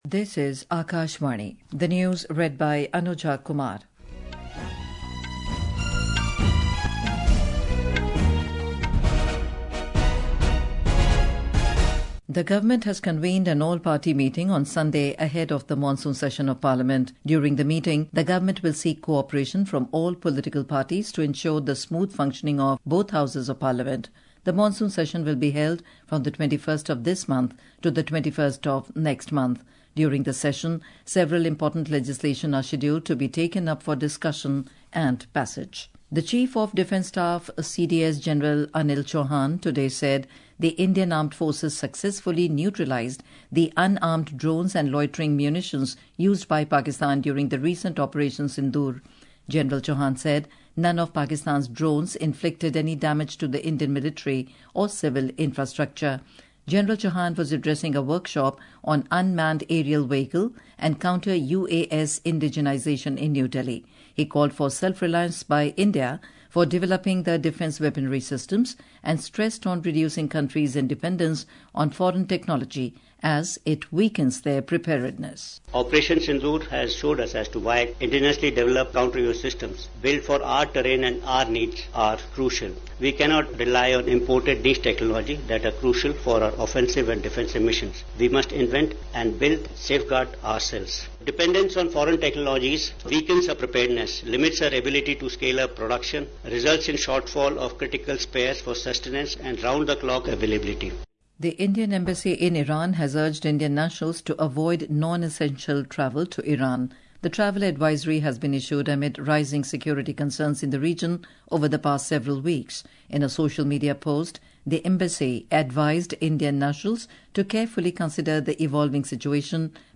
Hourly News Hourly News